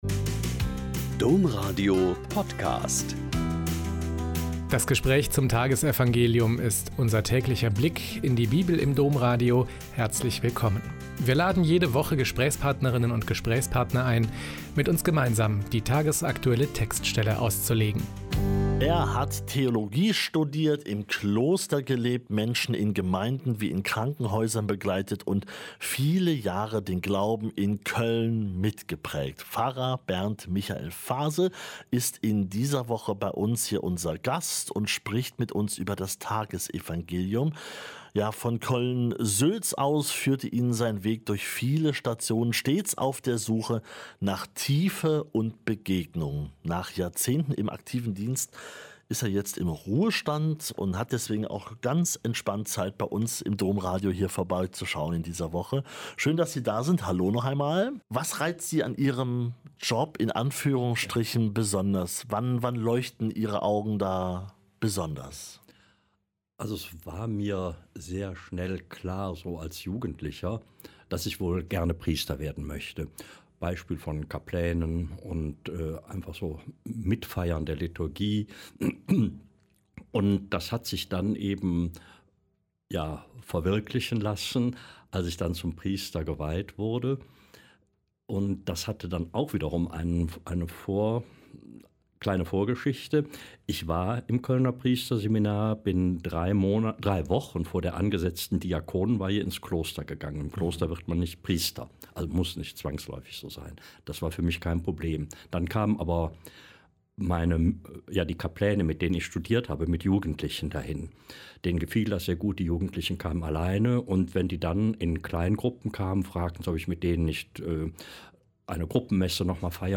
Lk 12,49-53 - Gespräch